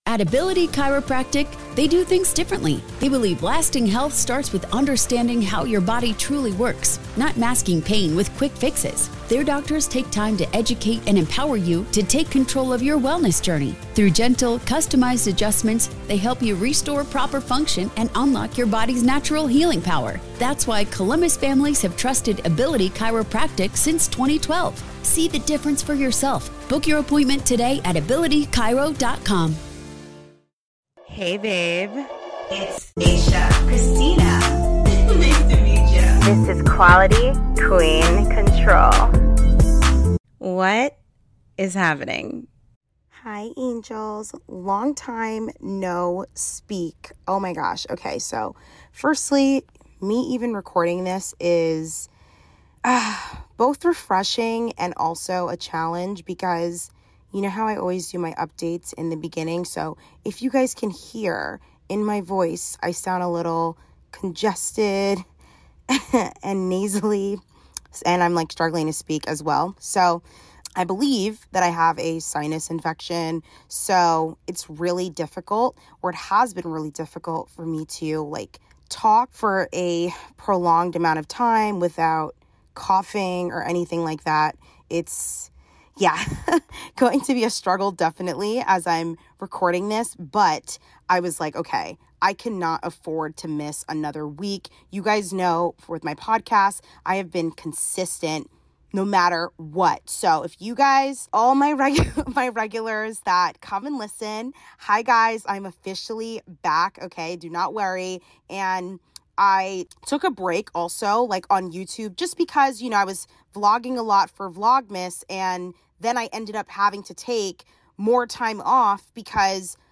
HI HELLO! ugh this was a struggle because I have such a bad cough!
Happy Wednesday Angels! it feels so good to be back and able to speak long enough without coughing!